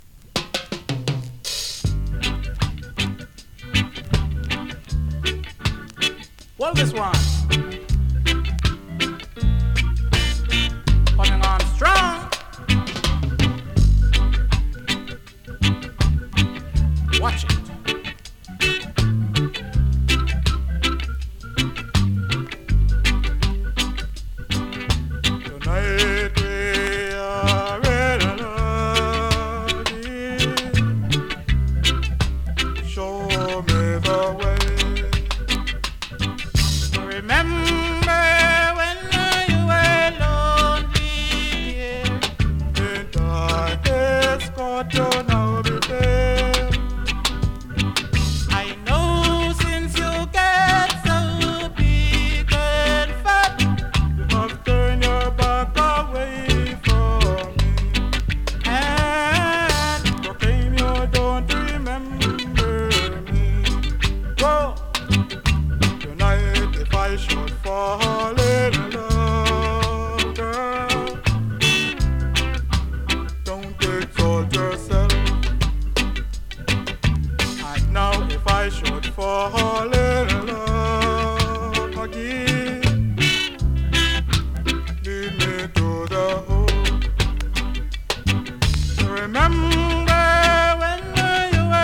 2026!! NEW IN!SKA〜REGGAE
スリキズ、ノイズかなり少なめの